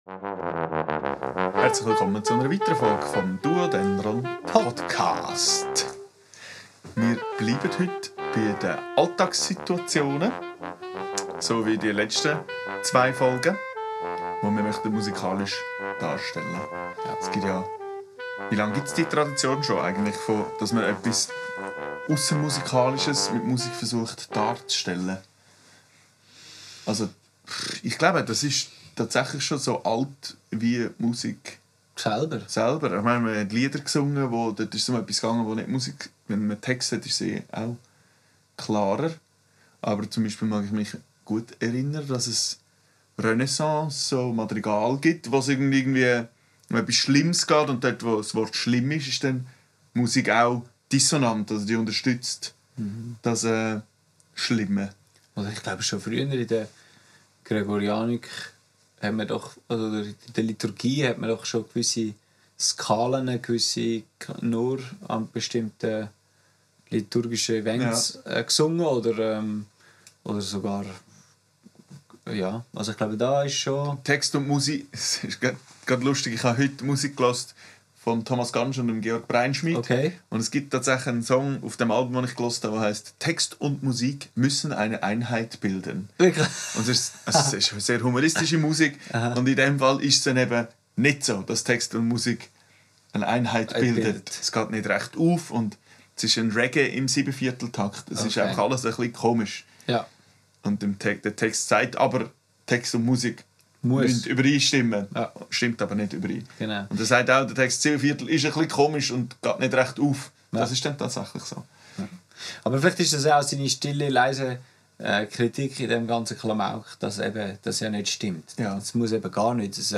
Frei improvisiert und frisch von der Leber gespielt.